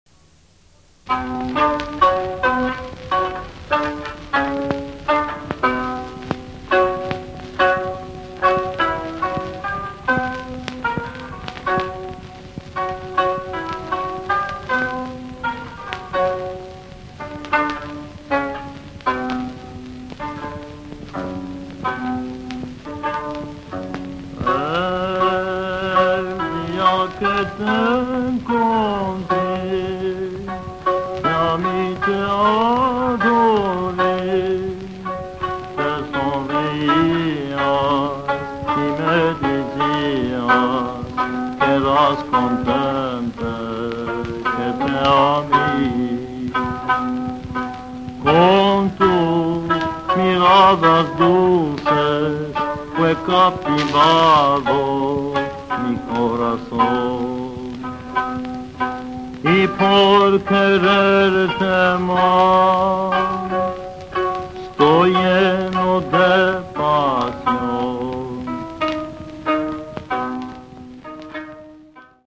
Where recorded: Los Angeles, CA, United States